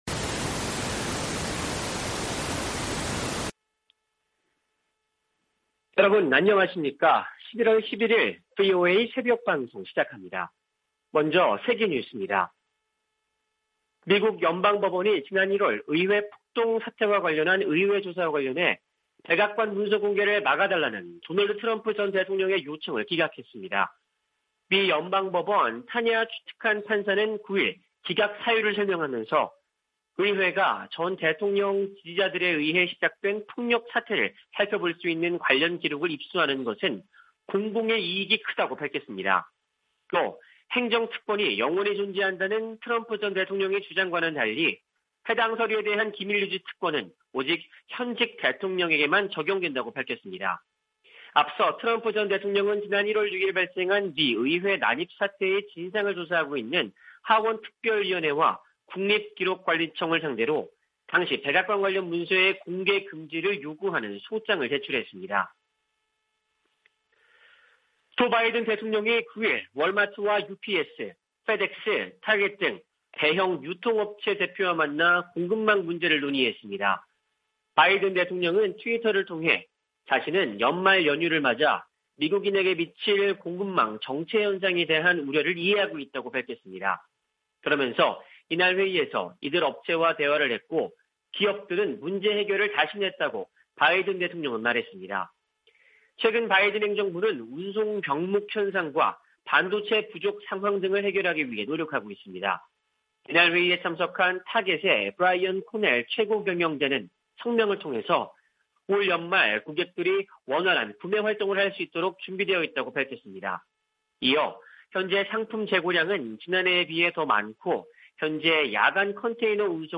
세계 뉴스와 함께 미국의 모든 것을 소개하는 '생방송 여기는 워싱턴입니다', 2021년 11월 11일 아침 방송입니다. '지구촌 오늘'에서는 미 상·하원의원들의 타이완 방문에 중국이 반발한 소식, '아메리카 나우'에서는 의사당 습격 사건 관련 문서 공개를 막아달라는 도널드 트럼프 전 대통령 측 요청을 법원이 기각한 이야기 전해드립니다.